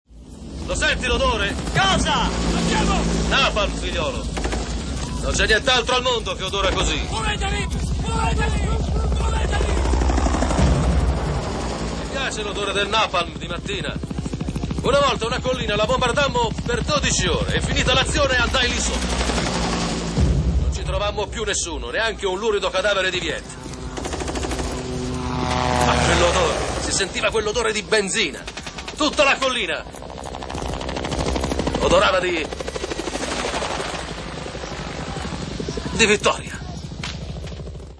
voce di Gianni Marzocchi nel film "Apocalypse Now", in cui doppia Robert Duvall.
vocegmarz.mp3